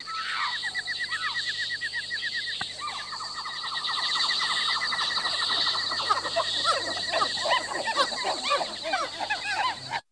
JUNGLE ATMOS.wav